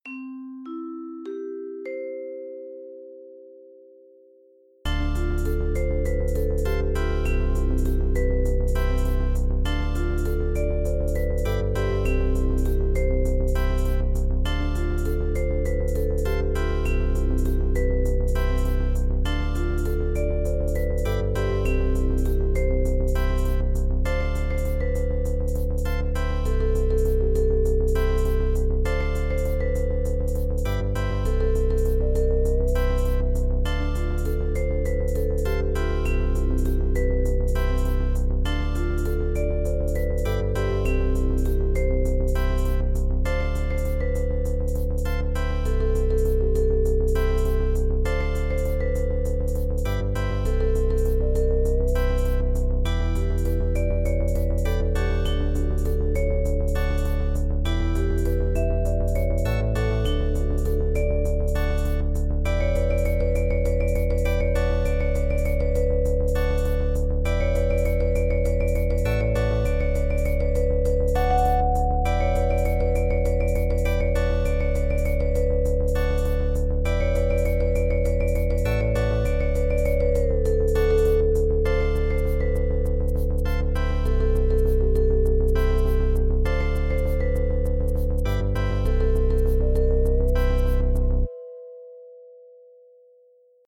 Well this definitely sounds like an elevator.